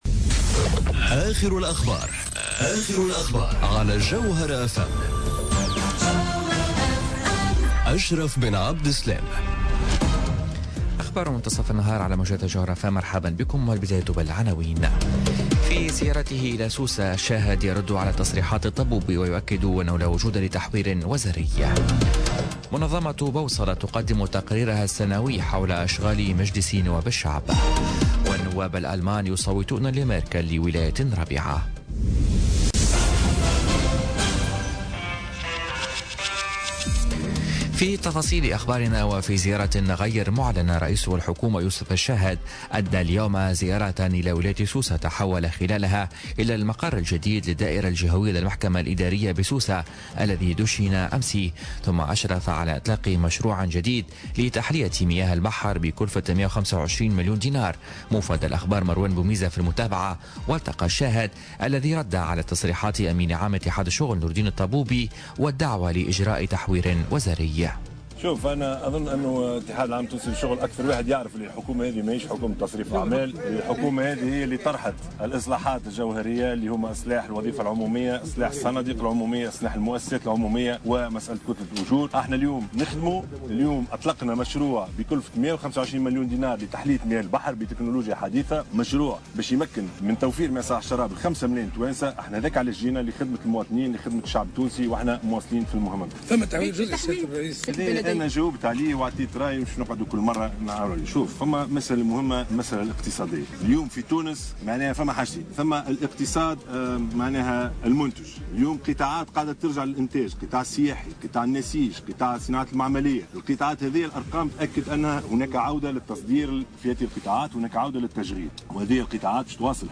نشرة أخبار منتصف النهار ليوم الإربعاء 13 مارس 2018